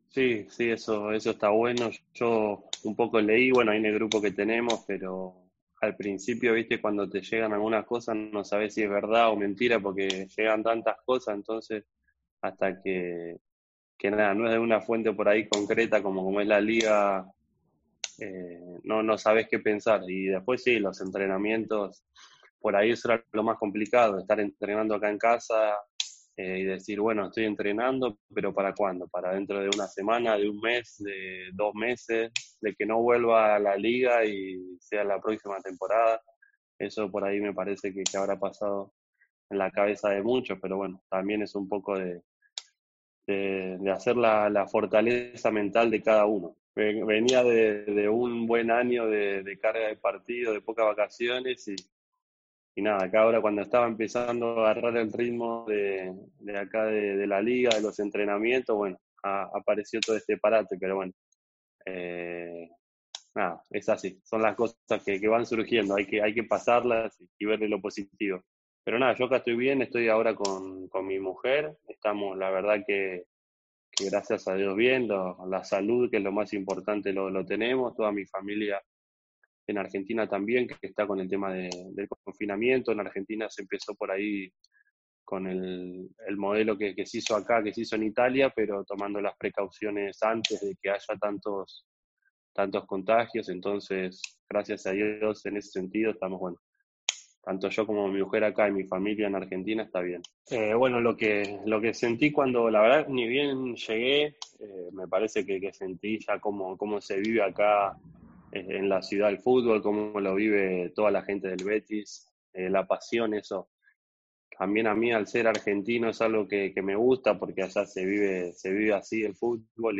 GUIDO RODRÍGUEZ, EN UNA ENTREVISTA CON LOS MEDIOS OFICIALES DEL BETIS